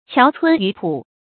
樵村渔浦 qiáo cūn yú pǔ
樵村渔浦发音